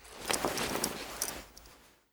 looting_4.ogg